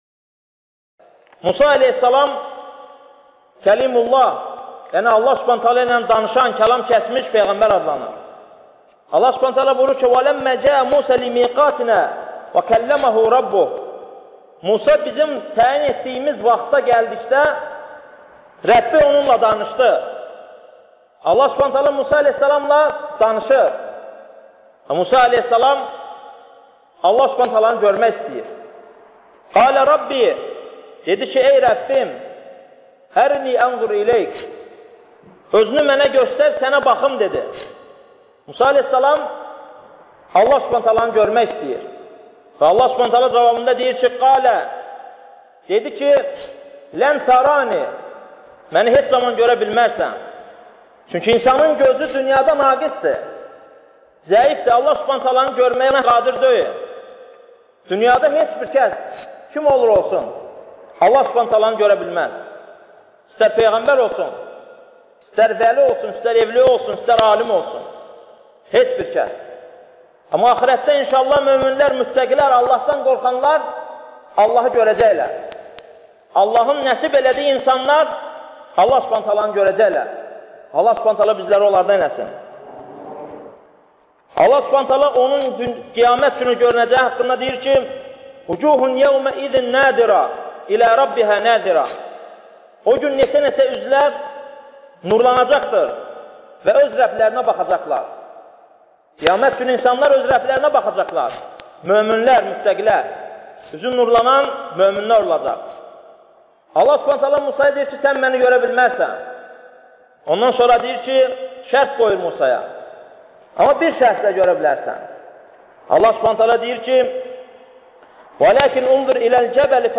Dərslərdən alıntılar – 100 parça